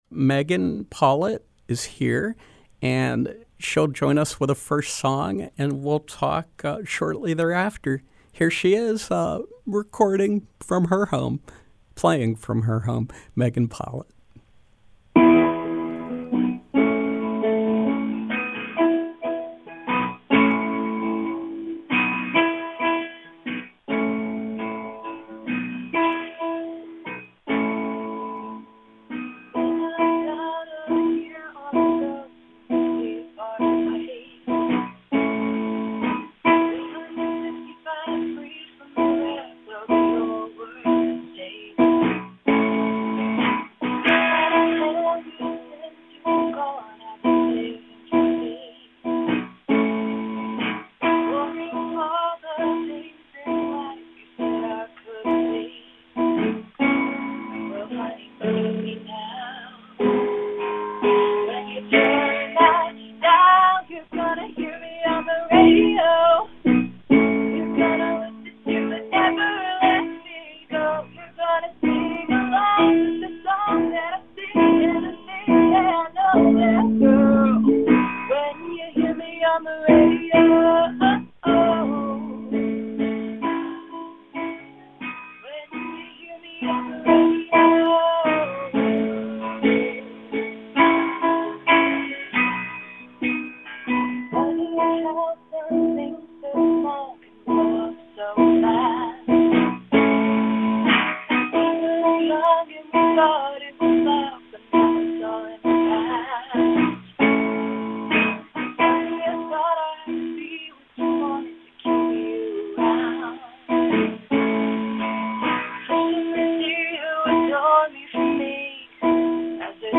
Singer-songwriter
joins us by phone for an interview and performance.